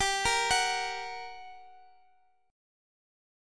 Listen to GmM7 strummed